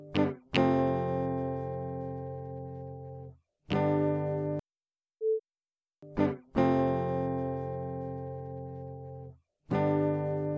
My plectrum when I record with my guitar makes a clicking noise.
Note the clicks at the beginning of each note.
It will be time consuming to go through every click, but here’s a before/after.
The sound before the beep is your original recording. The sound after the beep has had the plectrum clicks edited out. The beep indicates that one version has finished and the next version is about to start.